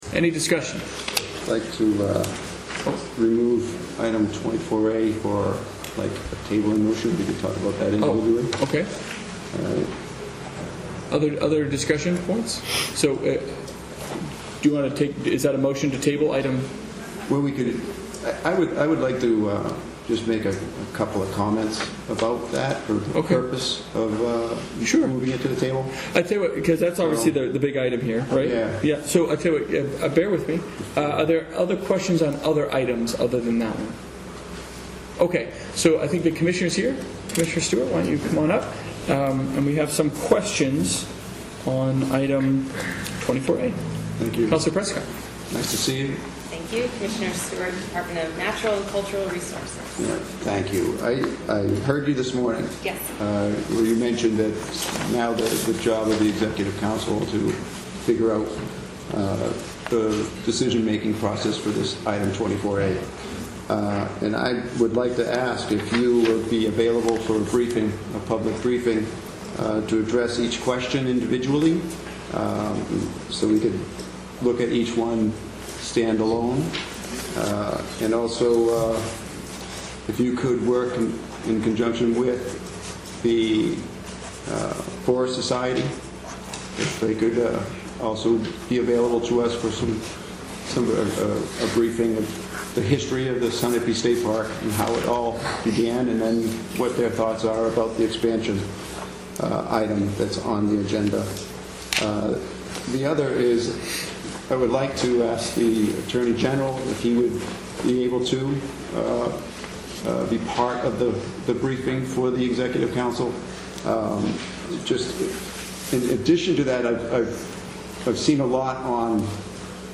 Here is the audio of the Executive Council discussion on Oct. 17, 2018, about Mount Sunapee and the proposed lease amendments.